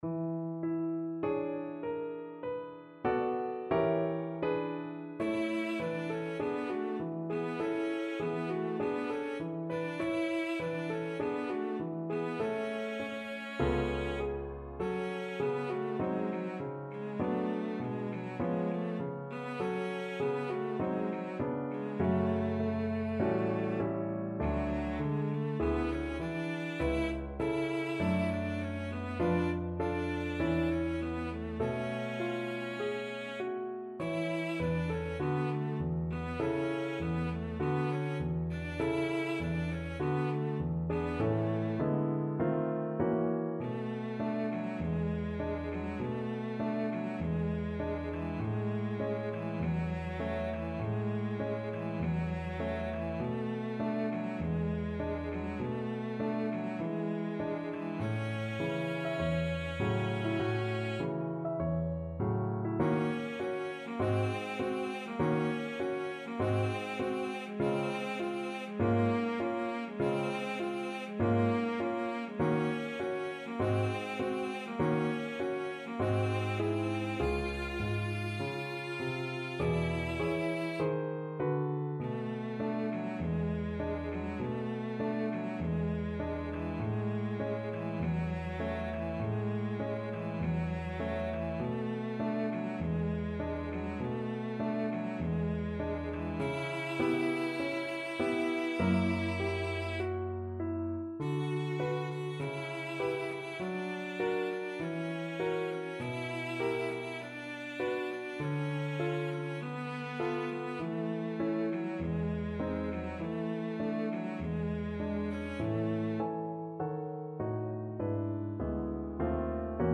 Moderato
4/4 (View more 4/4 Music)
Pop (View more Pop Cello Music)
film (View more film Cello Music)